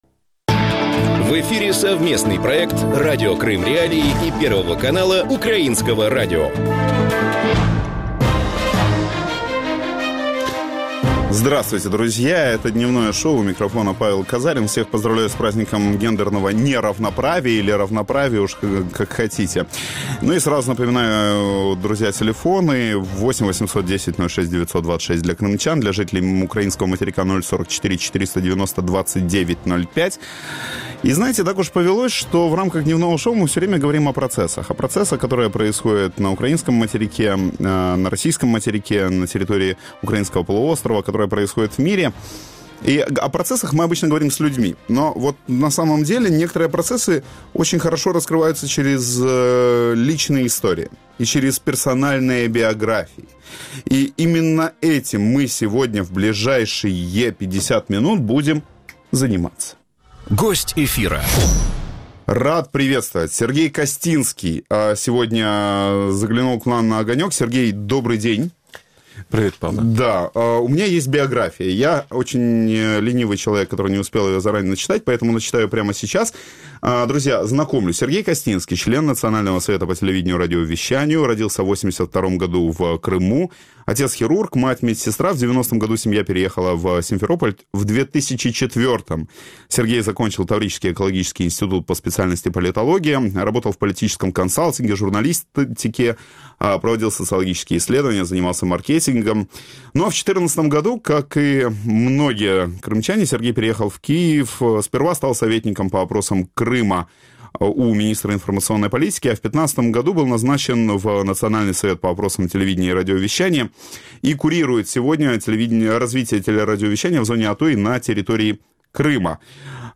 Гость студии – член национального совета Украины по вопросам теле и радиовещания Сергей Костинский.